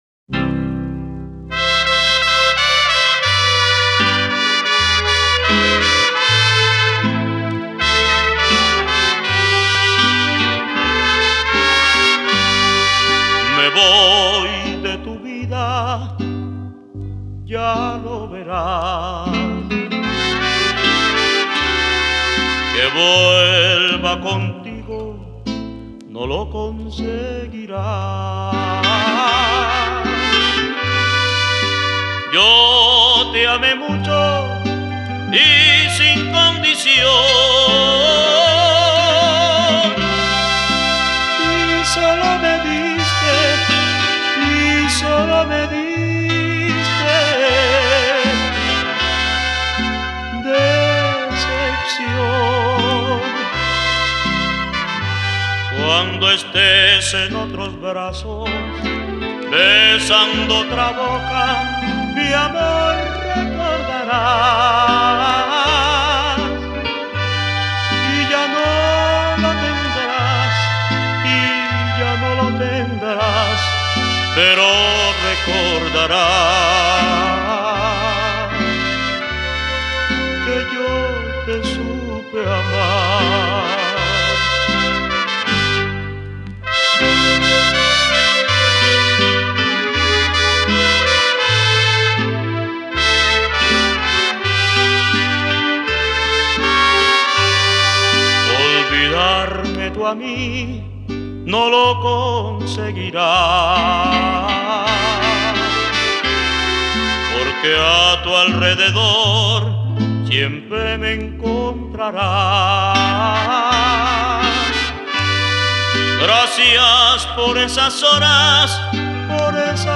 Ranchera